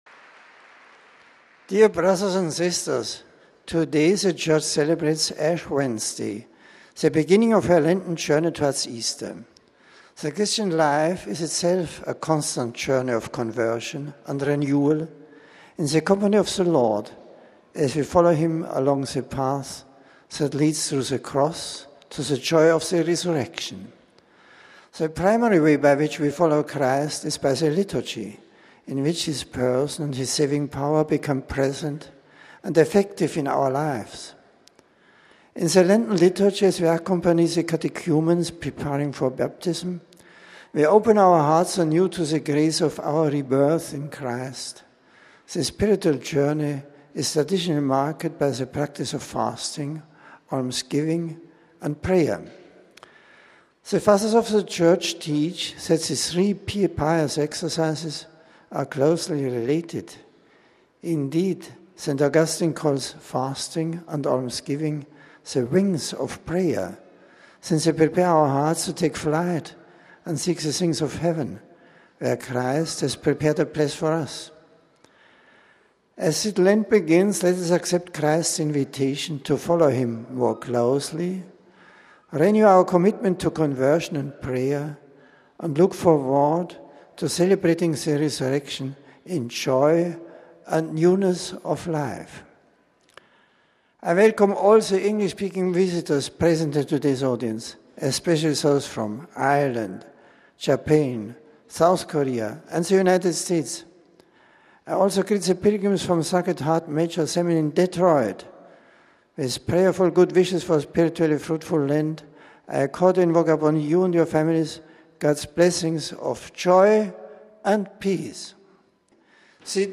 The general audience of March 9, Ash Wednesday, was held in the Vatican’s Paul VI Audience Hall. A scripture passage was read in several languages. An aide greeted the Pope on behalf of the English-speaking pilgrims, and presented the various groups to him. Pope Benedict’s discourse was obviously on Ash Wednesday, the first day of the 40-day Lenten period that culminates in Christianity’s most solemn feast of Easter, the commemoration of Jesus rising from death.